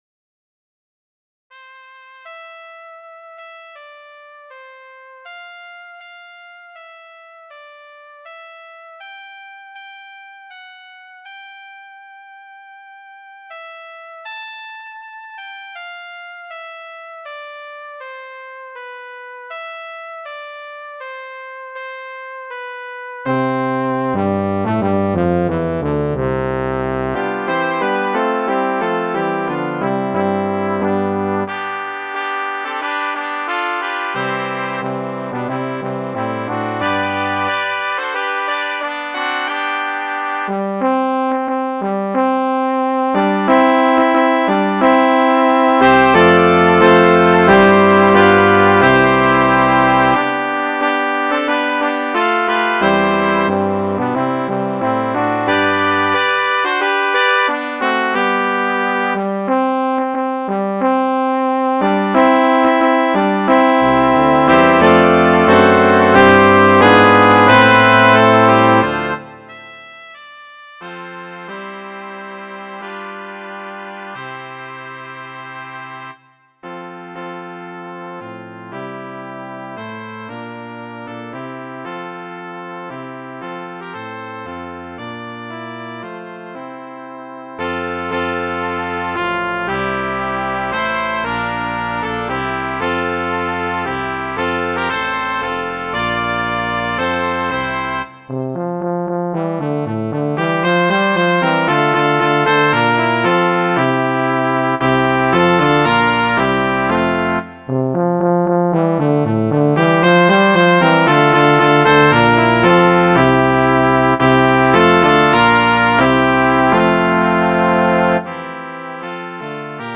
arranged for brass band